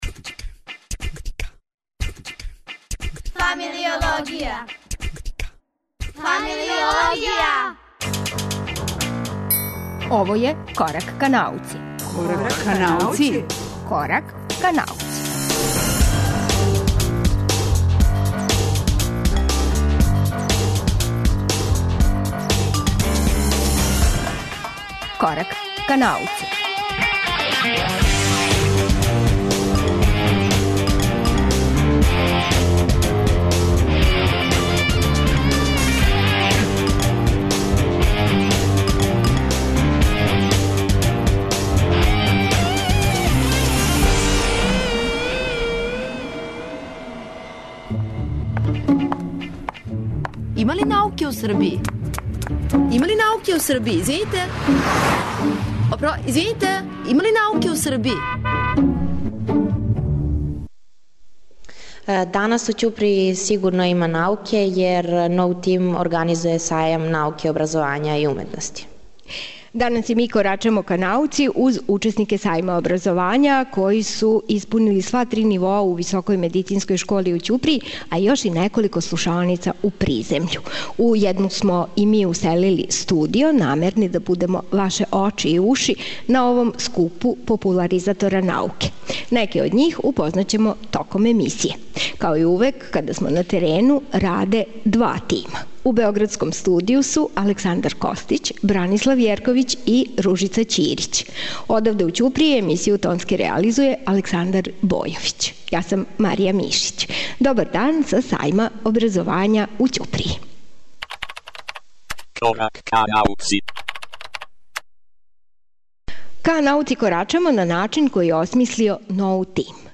Емисију емитујемо уживо из Ћуприје са Четвртог сајма науке и образовања који организује НОУТИМ.